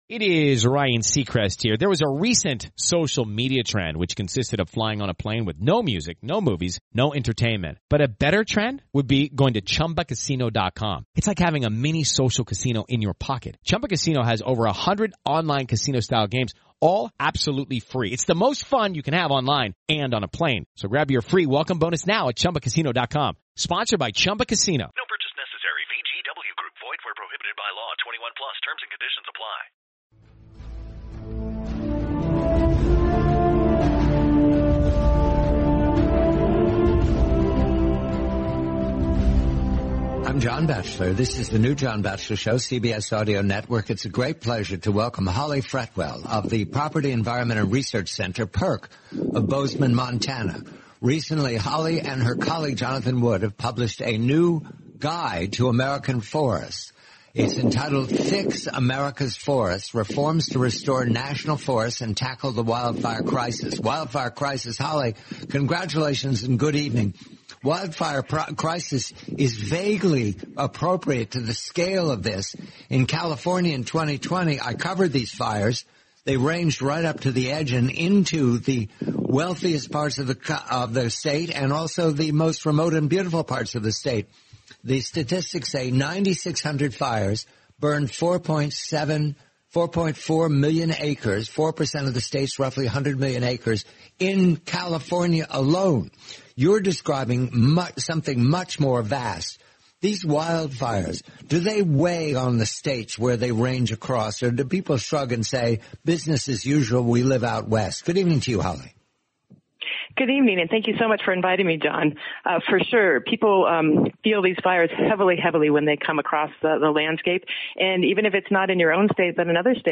The complete, forty-minute interview.